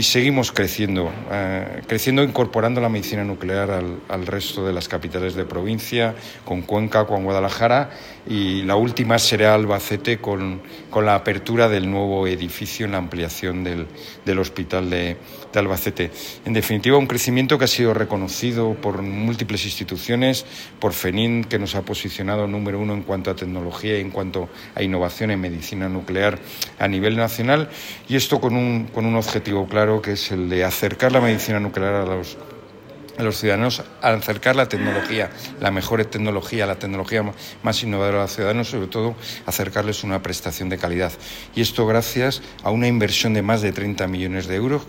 corte_gerente_sescam_medicina_nuclear.mp3